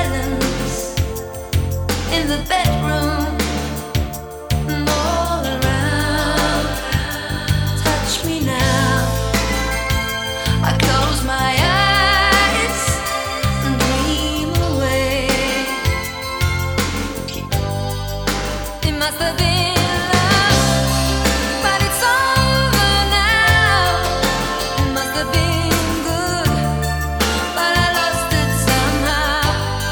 • Vocal